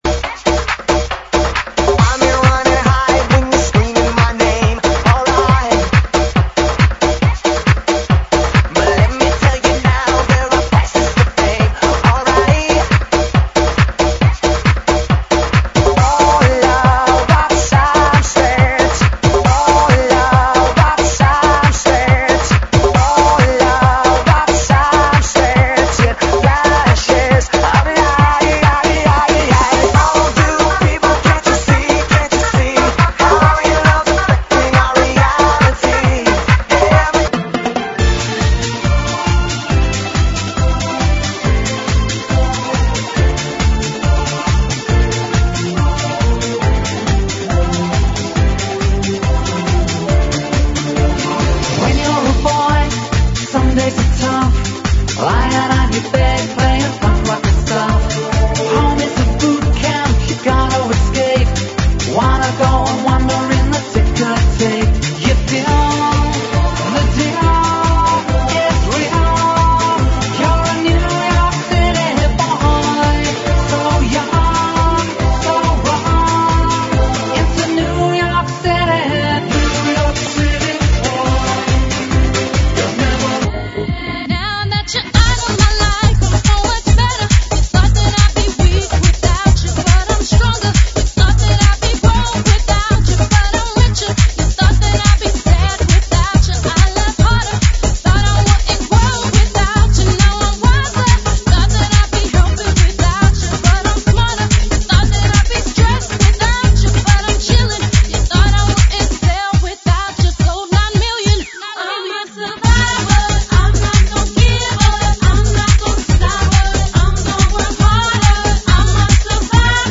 GENERO: POP – 80S – 90S – REMIX